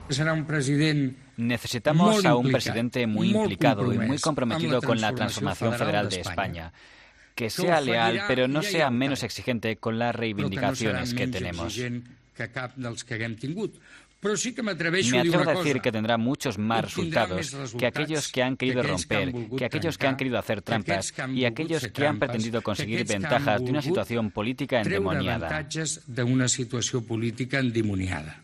En declaraciones a los medios tras intervenir en la III Convención Federalista organizada por la Fundació Campalans y la Fundación Friedrich Ebert Stiftung, ha bromeado al afirmar: "la receta es Iceta".